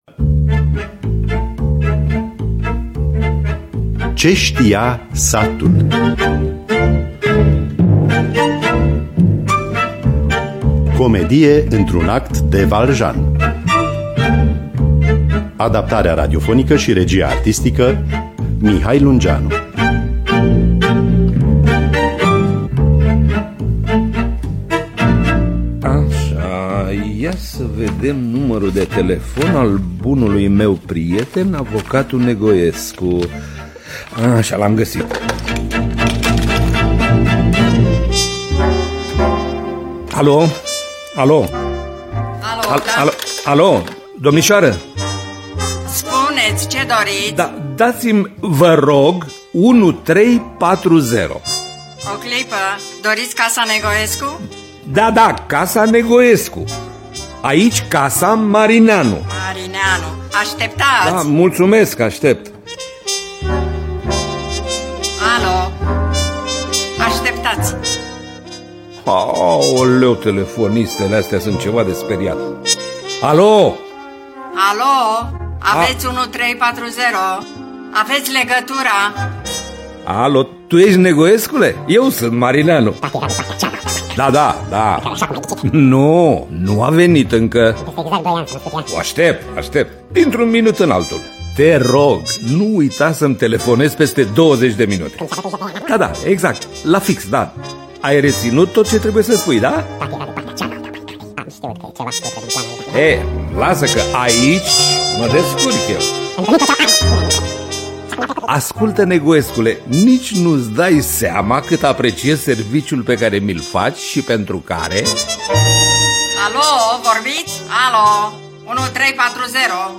“Ce știa satul” de Ion Valjan (Ion Alexandru Vasilescu). Adaptarea radiofonică